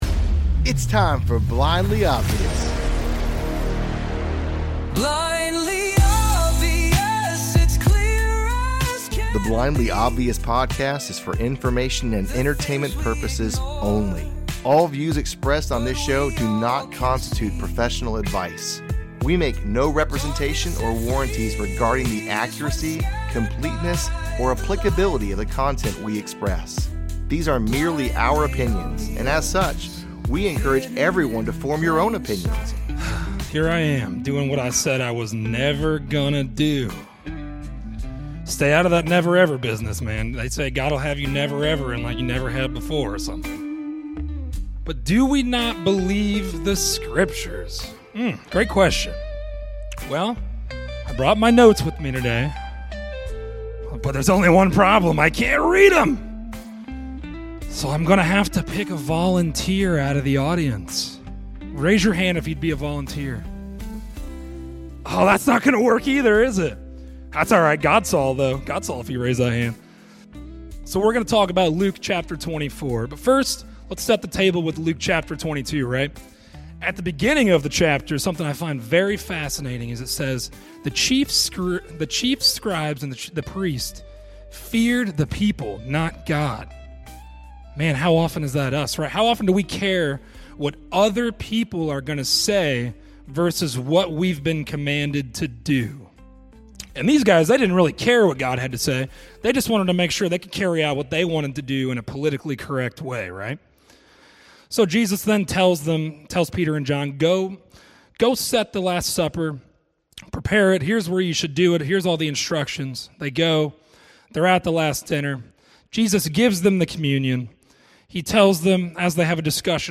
shared a message with his home Church about how he too came to recognize and accept Jesus.